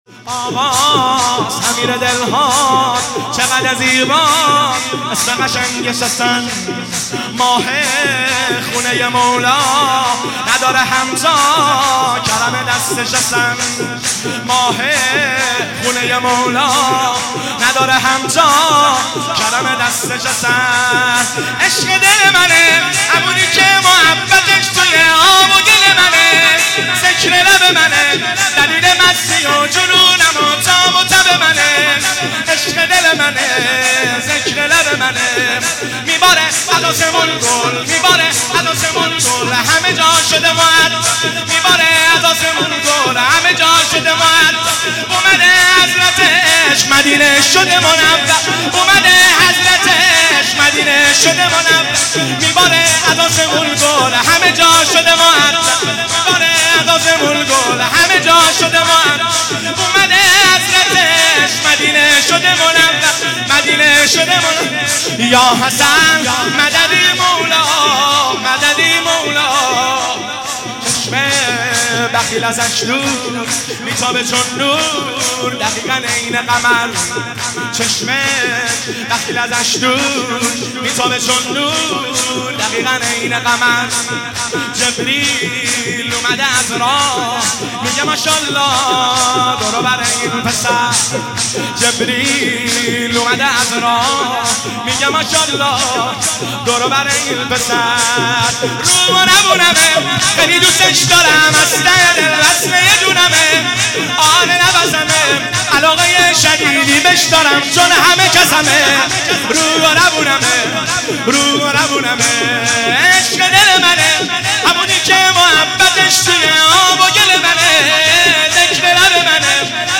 میلاد امام حسن مجتبی (ع)